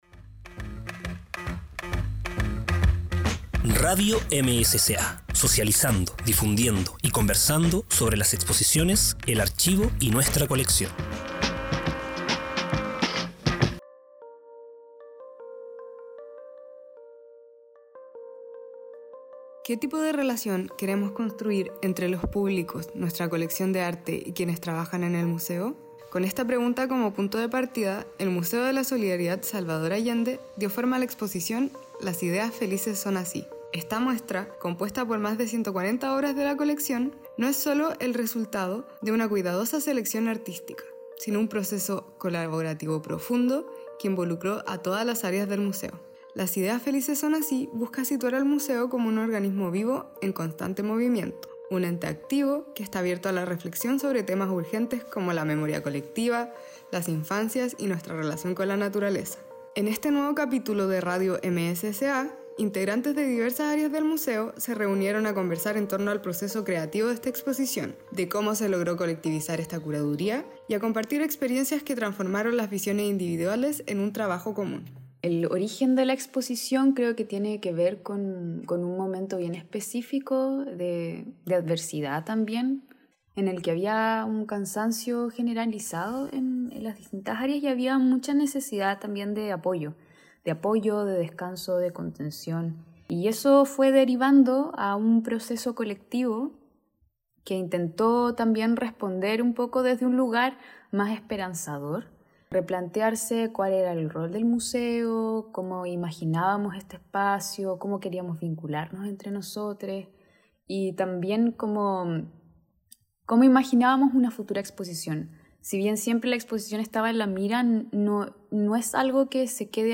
Resumen: En este capítulo conversamos en torno a nuestra nueva exposición, Las ideas felices son así, muestra que fue imaginada y desarrollada de manera colectiva por todo el Equipo del MSSA. Distintas trabajadoras y trabajadores del Museo nos cuentan sobre su experiencia participando en esta exposición.